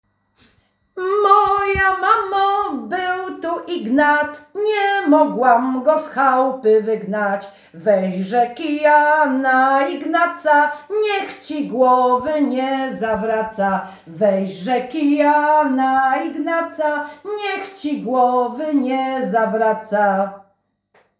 Moja mamo, był tu Ignac – Żeńska Kapela Ludowa Zagłębianki
Nagranie współczesne